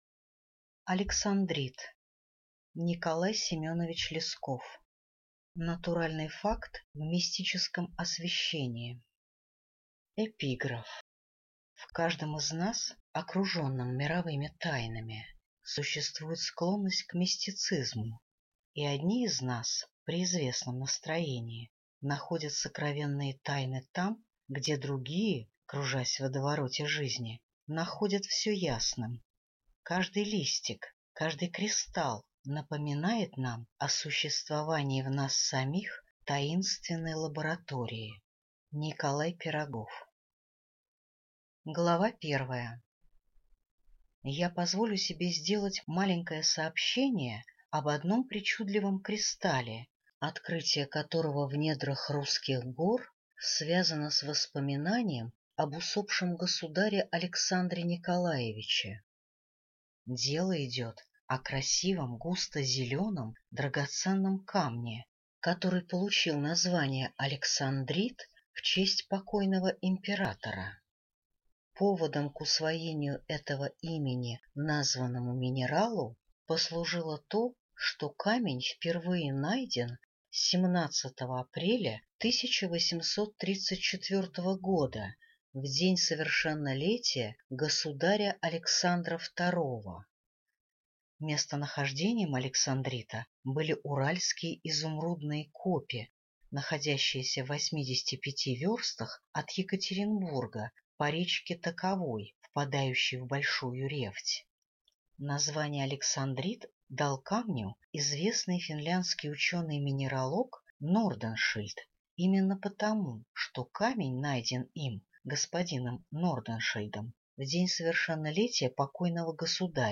Аудиокнига Александрит | Библиотека аудиокниг